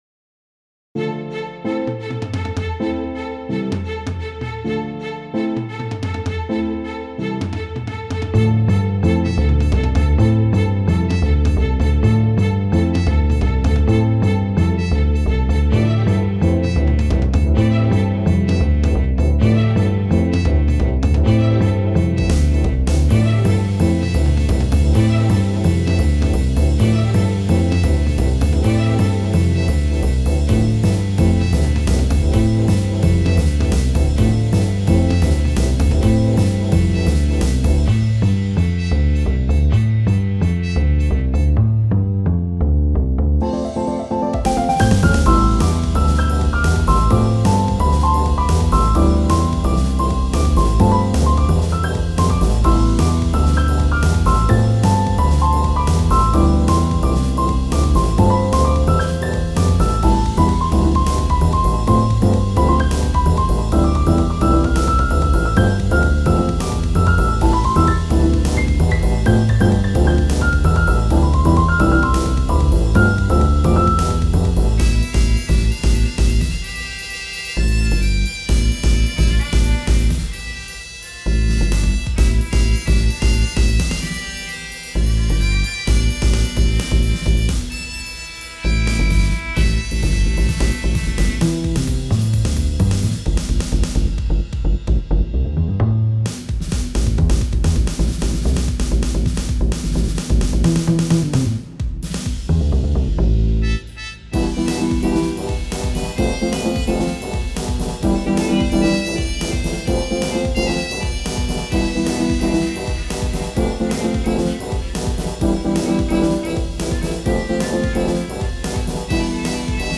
BGM
ジャズロング